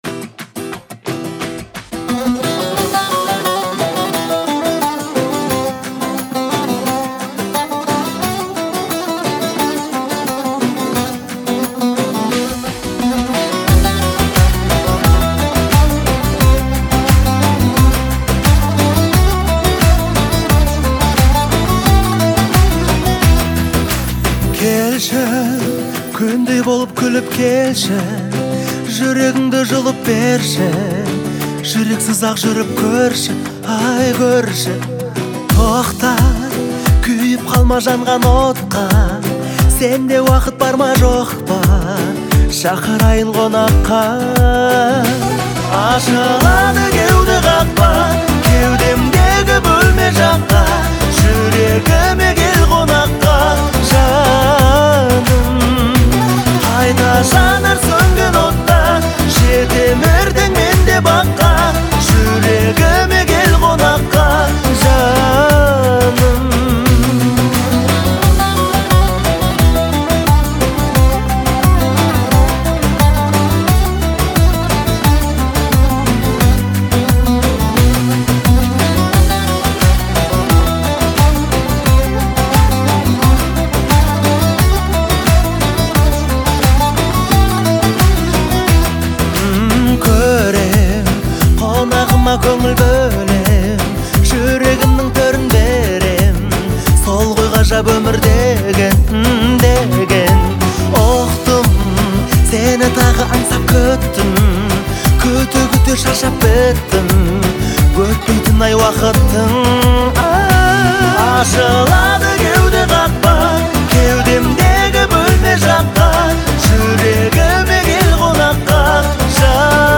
Песня выделяется мелодичным звучанием и душевным вокалом